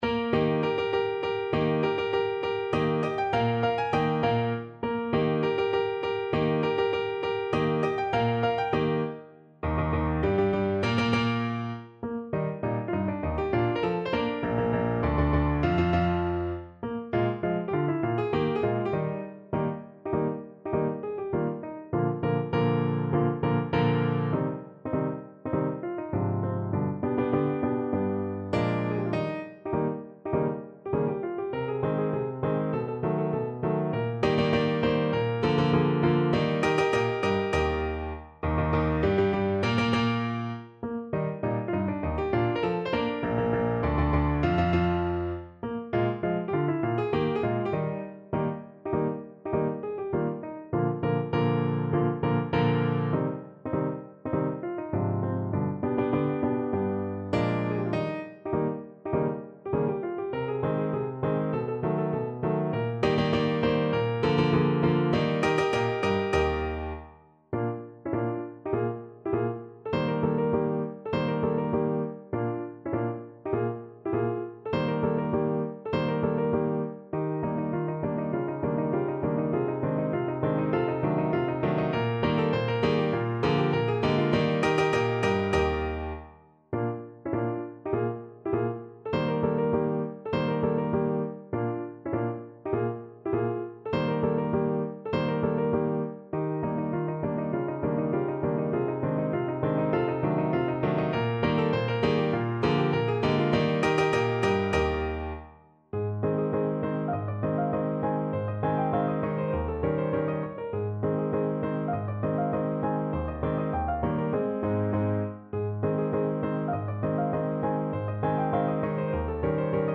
2/2 (View more 2/2 Music)
Eb major (Sounding Pitch) (View more Eb major Music for Voice )
Traditional (View more Traditional Voice Music)